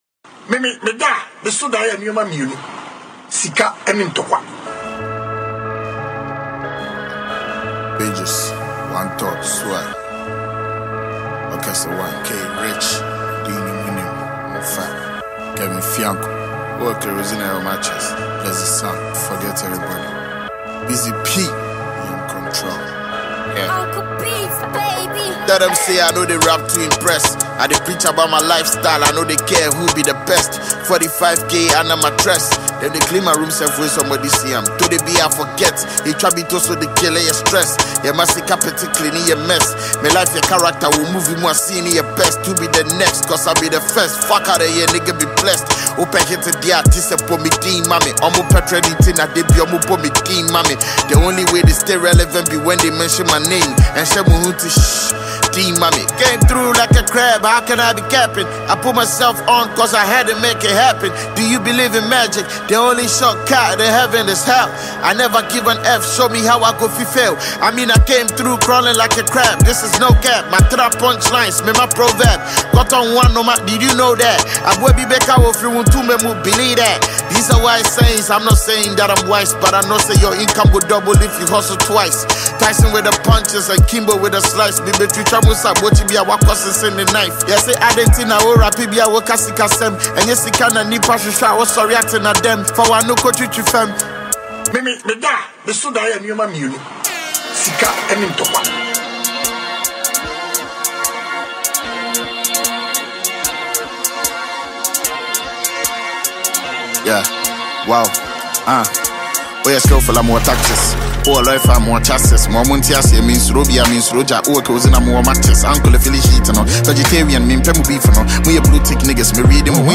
Ghana MusicMusic